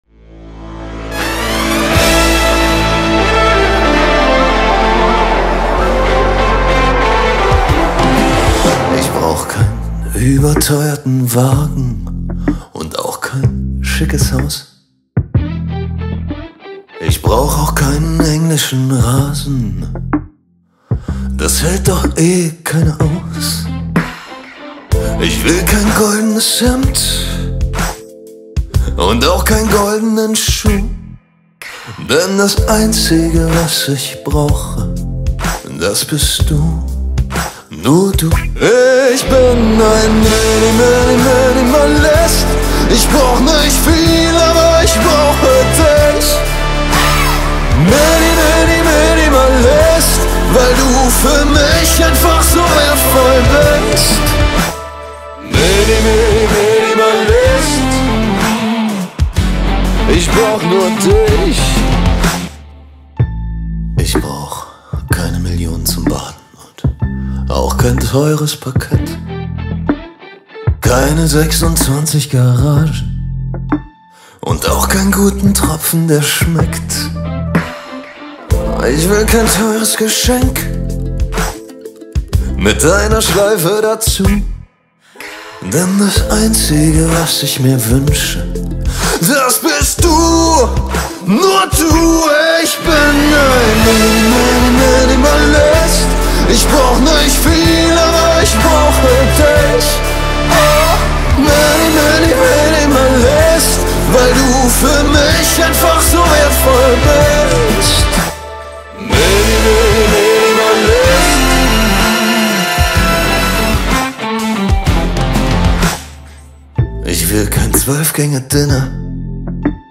Specialized in Pop, Rock/Hard Rock, Indie, and Funk.